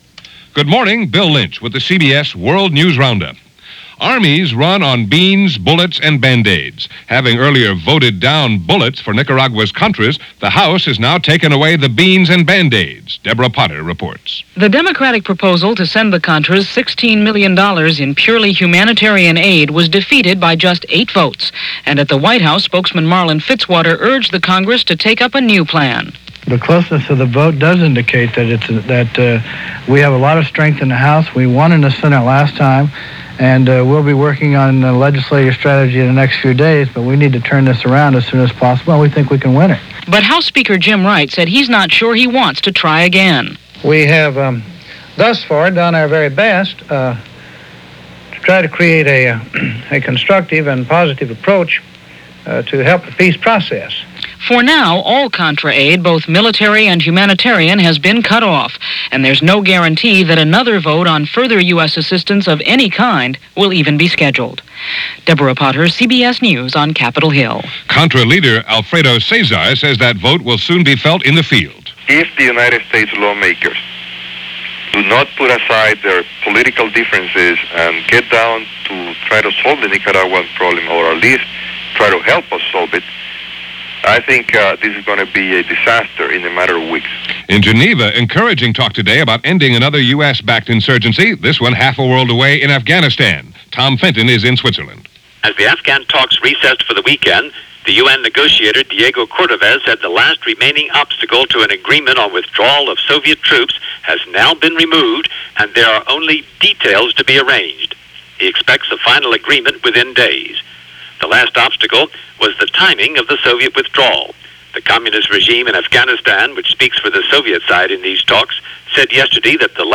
Strange day, and along with the ongoing Contra issues on Capitol Hill, that’s just a small slice of what went on, this March 4, 1988 as reported by The CBS World News Roundup.